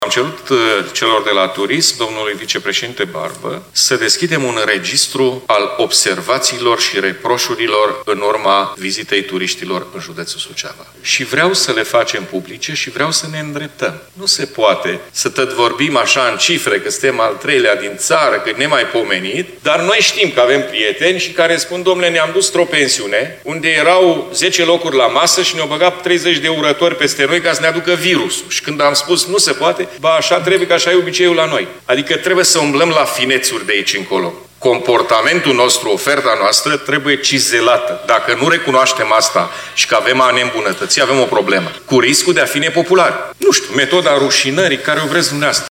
El a declarat astăzi, în cadrul ședinței legislativului județean, că o parte dintre concluzii ar putea fi făcute publice, pentru a se reuși îmbunătățirea serviciilor.